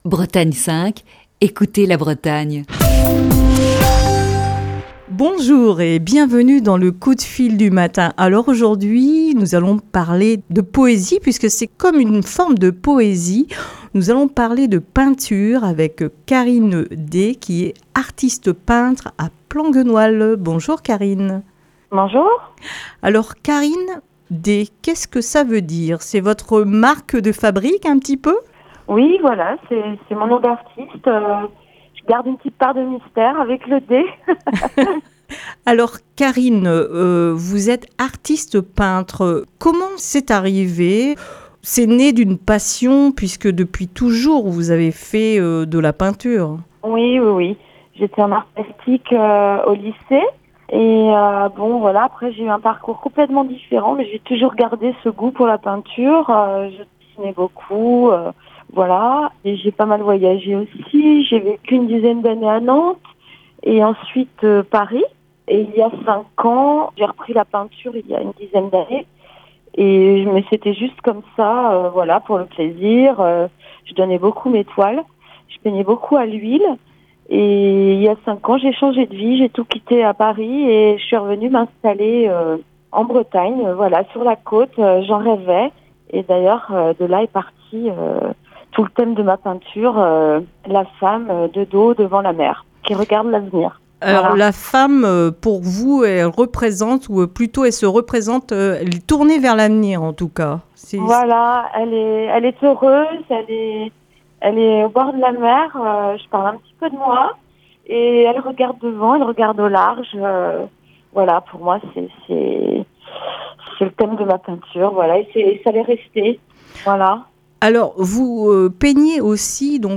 Ce mercredi dans le coup de fil du matin